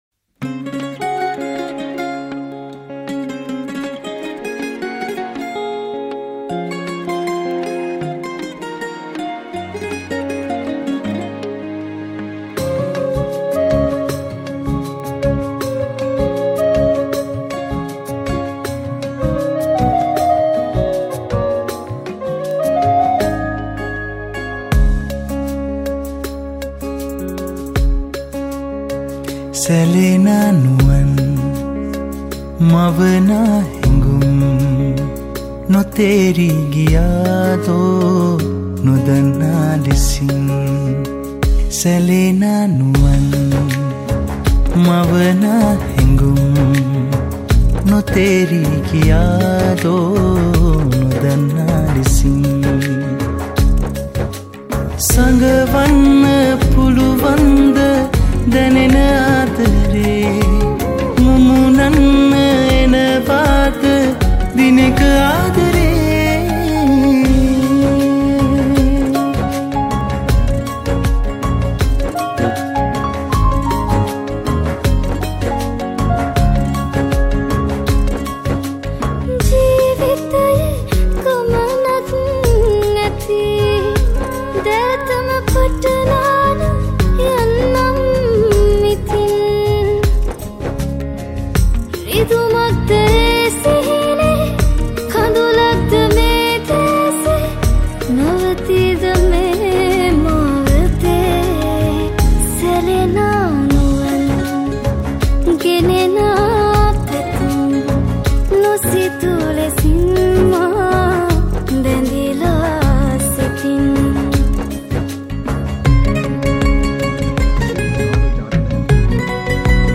Teledrama Song